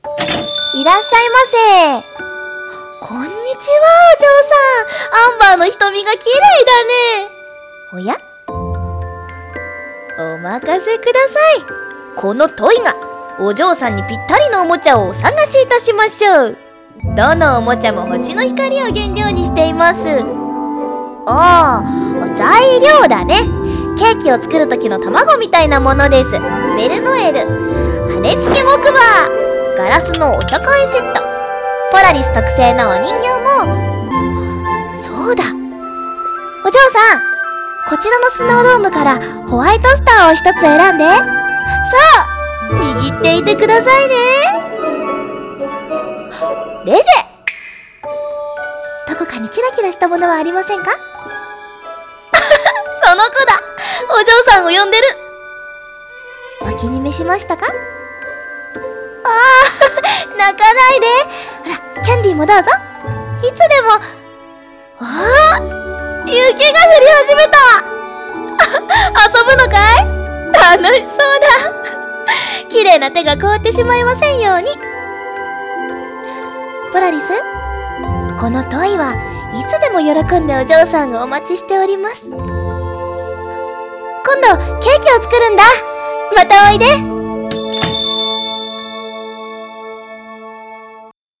【声劇】星のおもちゃ屋さん